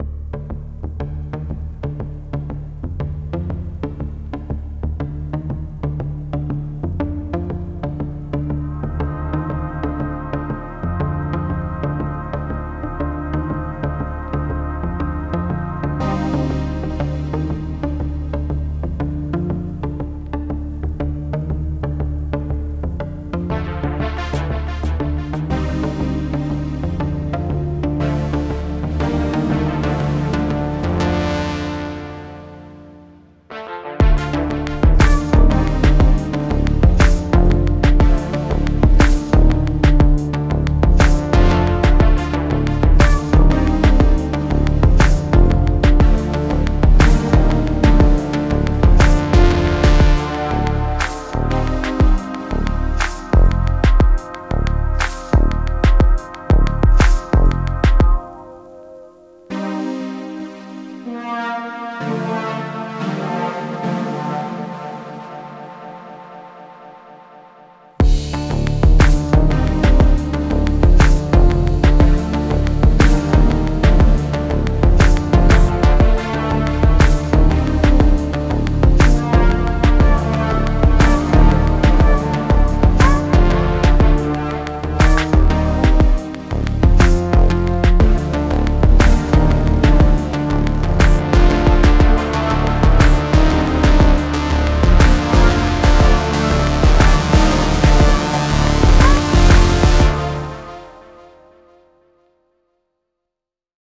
Tense, repetitive synth line with a menacing beat.